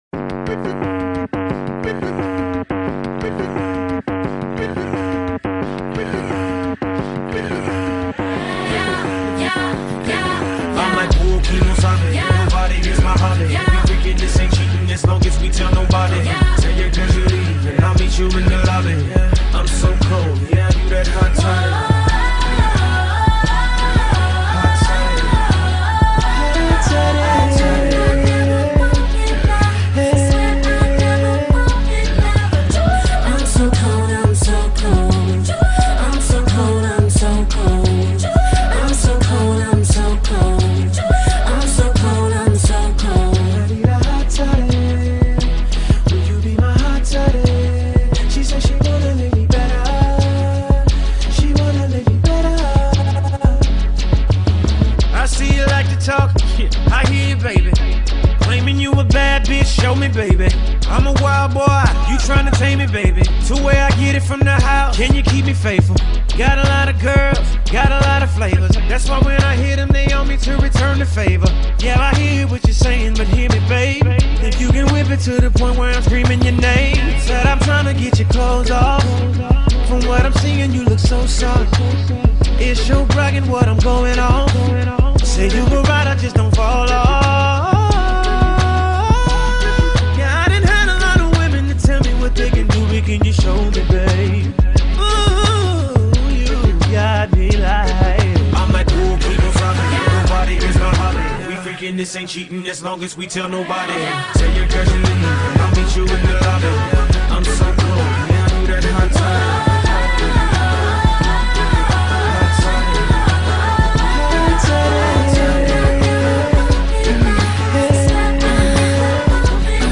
Music, R&B, Video